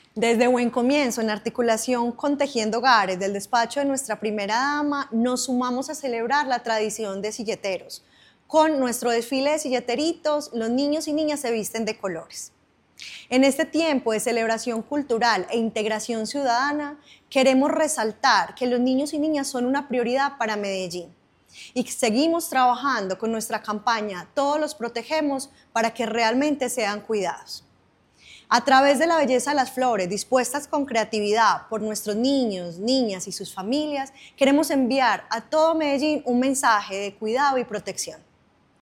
Declaraciones directora de Buen Comienzo, Diana Carmona